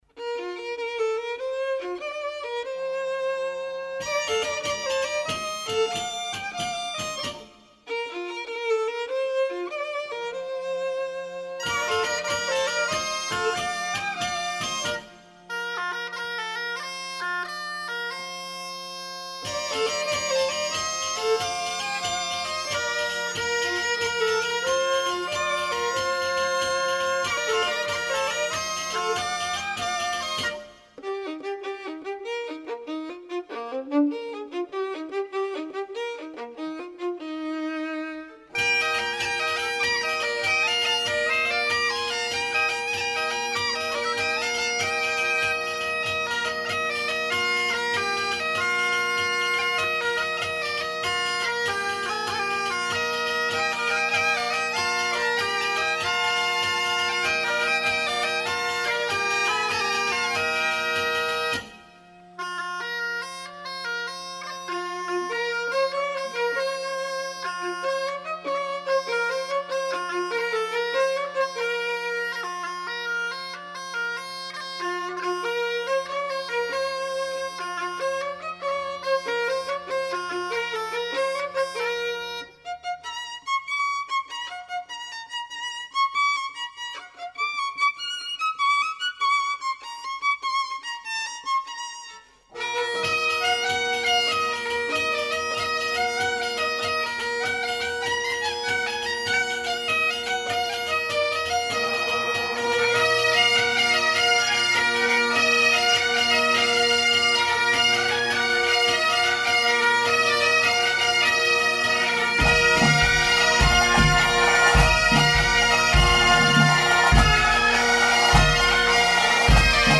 muineiras_da_valina_p892.mp3 (2.75 MB)
Galician music, bagpipe, gaita, percussion instruments, musical genres, Celticity